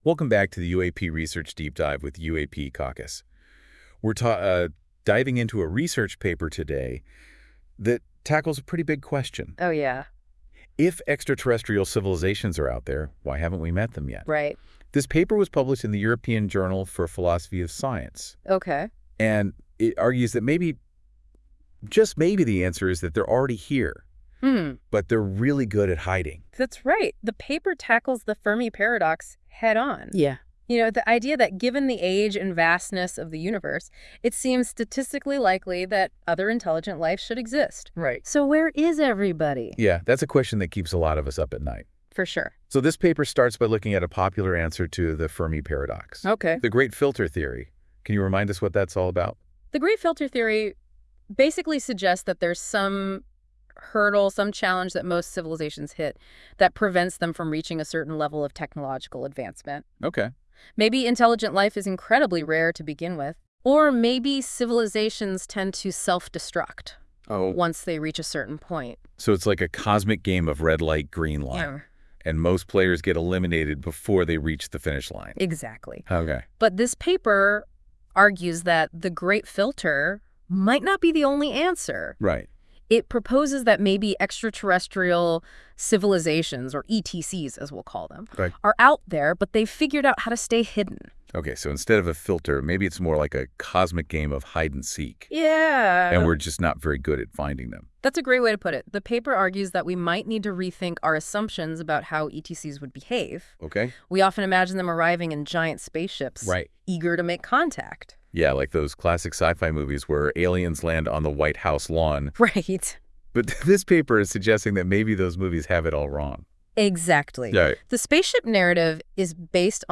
This AI-generated audio may not fully capture the research's complexity.
Audio Summary